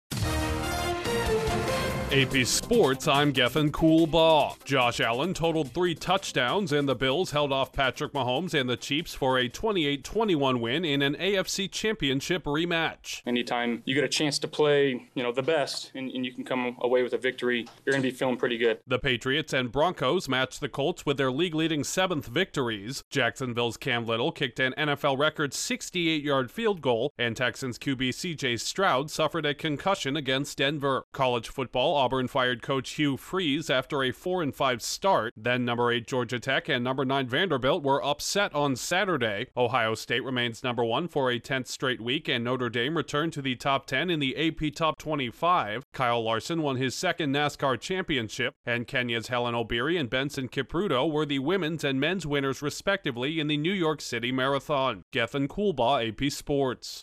Headliner Embed Embed code See more options Share Facebook X Subscribe Josh Allen bests Patrick Mahomes in latest clash of star quarterbacks, three teams share the NFL lead in wins, a record-long field goal and a star QB is injured, an SEC football coach is fired and two top 10 upsets result in AP Top 25 changes, a two-time NASCAR champ and results from the NYC Marathon. Correspondent